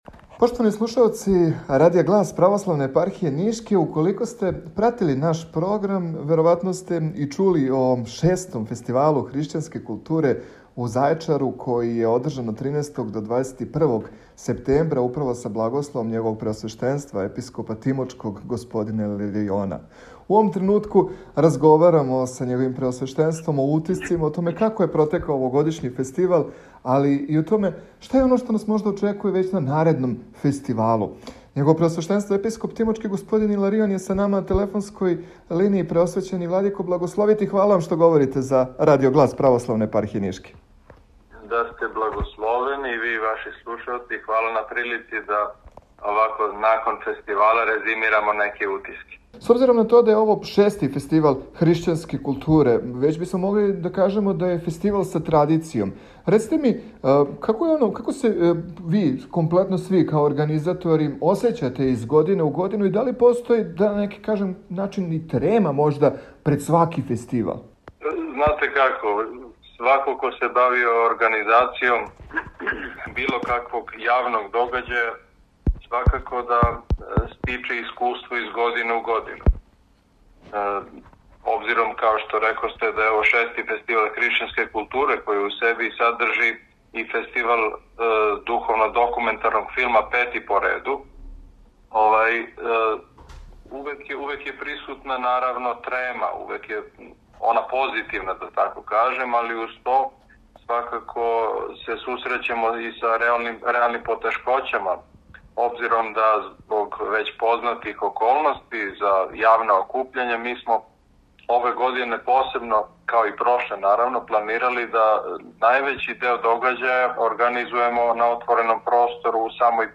Његово Преосвештенство Епископ тимочки Г. Г. Иларион у ексклузивном телефонском укључењу у програм Радија Глас, говорио је о IV Фестивалу Хришћанске културе који је у организацији Епархије тимочке одржан од 13. до 21. септембра 2021. године у Зајечару.